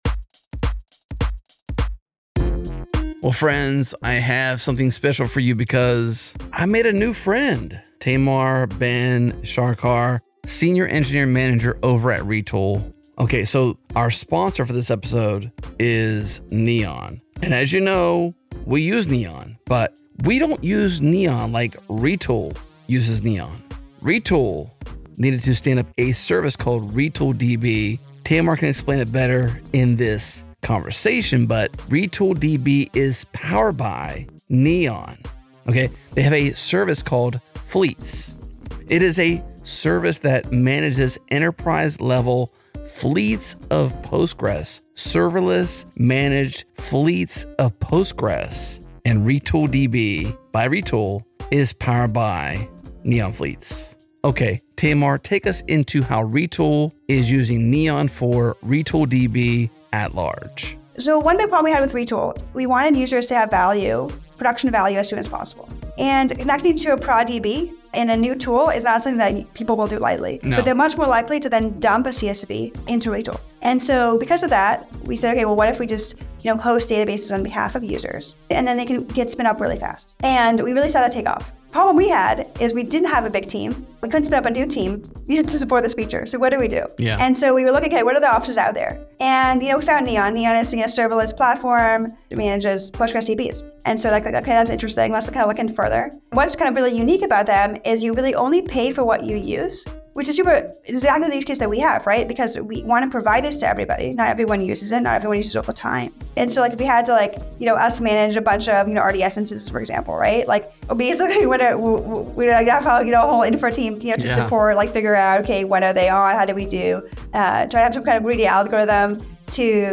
We like to get creative and our audience loves the conversational nature of our ad spots.
Customer Story